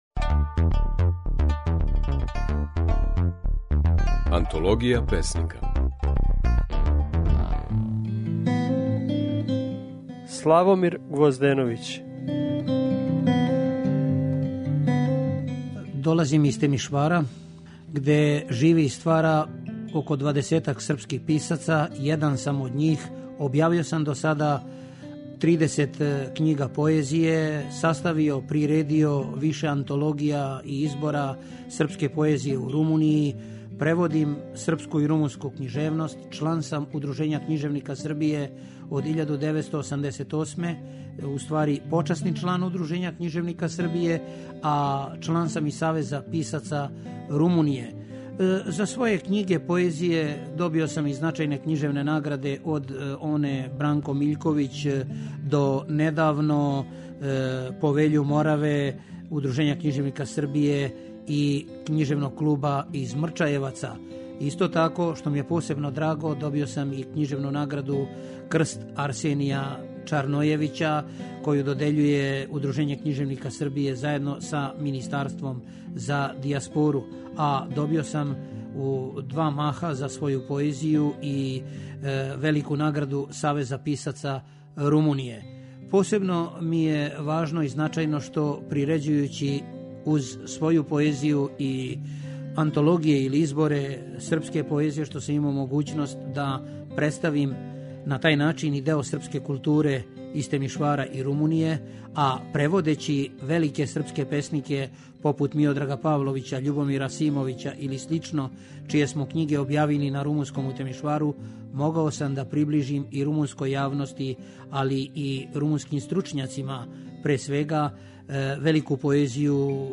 У данашњој емисији можете чути како своје стихове говори песник Славомир Гвозденовић (1953, Белобрешка, Румунија)